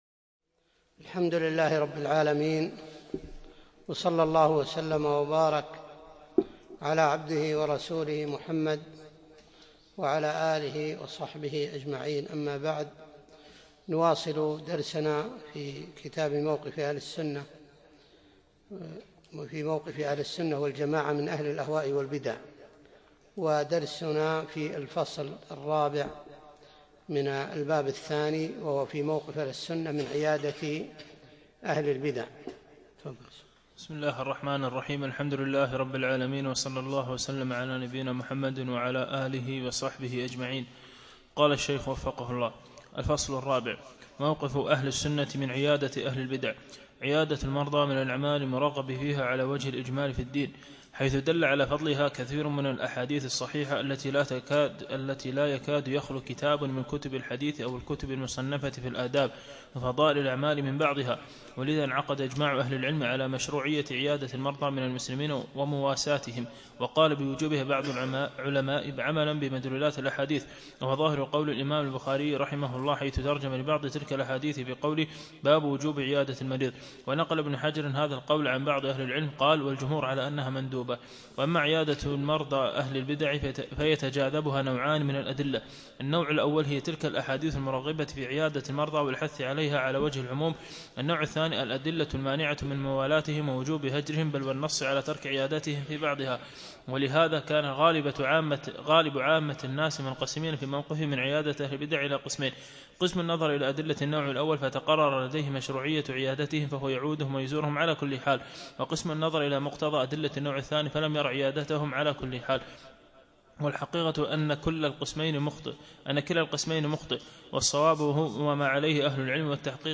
بعد المغرب يوم الأربعاء 22 جمادى الأول 1437هـ الموافق 2 3 2016م في مسجد كليب مضحي العارضية
الدرس الثامن : موقف أهل السنة من عيادة أهل البدع